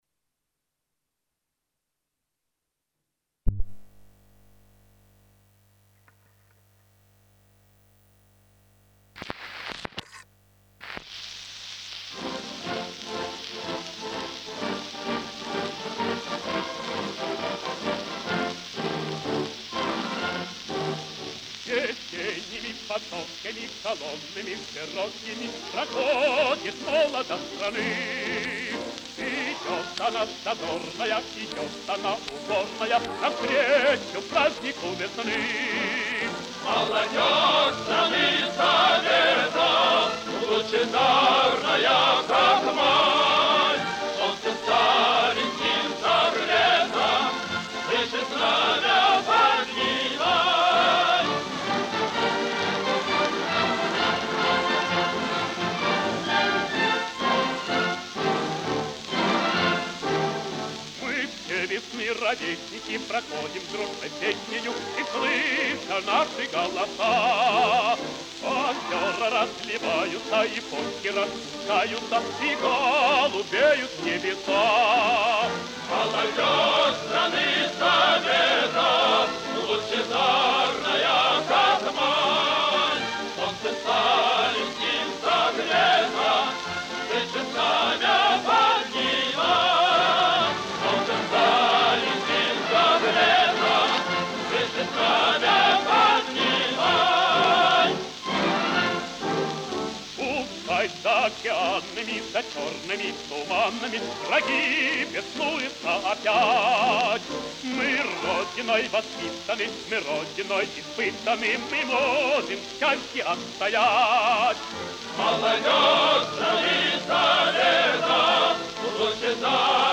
советcкий певец (лирический баритон).
Пластинка и запись 1950 года.